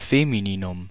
Ääntäminen
IPA : /ˈfɛmɪnɪn/